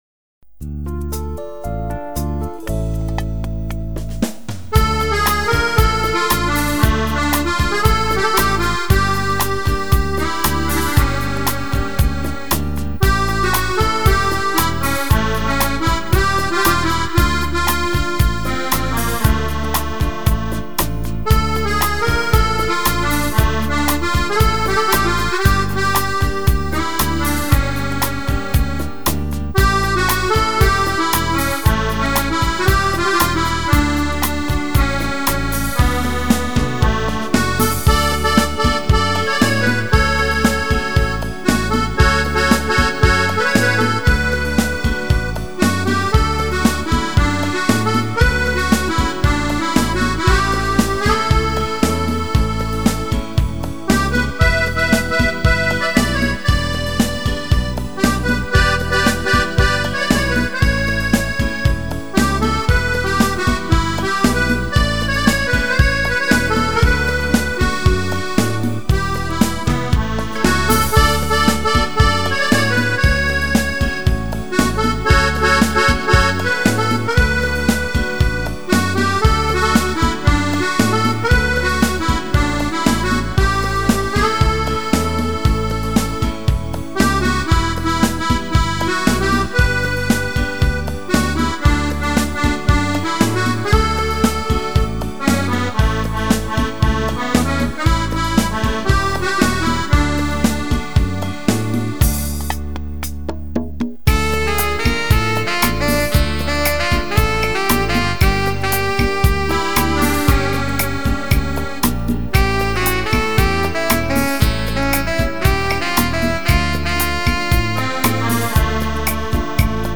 Canzoni e musiche da ballo
rumba / beguine